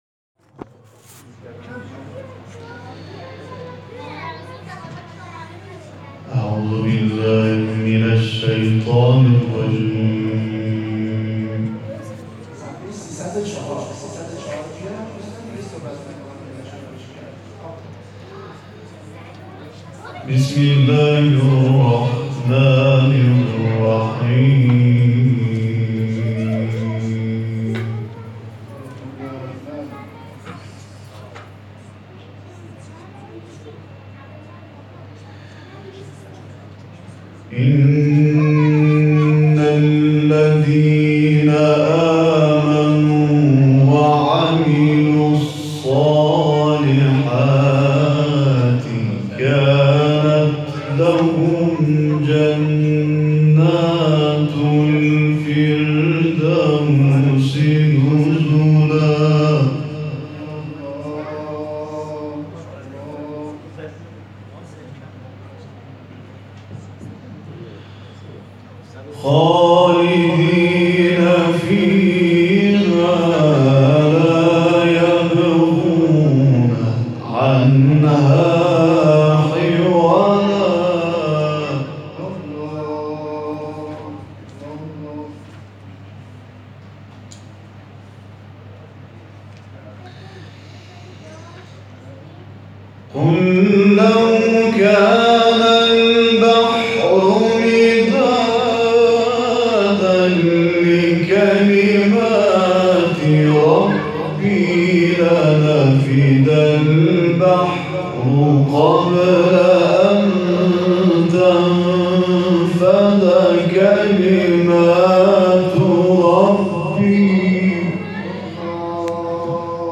تلاوت سور مبارکه کهف و شمس
در جمع نمازگزاران مسجد 14 معصوم منطقه 22 تهران واقع در بلوار امیرکبیر شهرک گلستان اجرا کرد.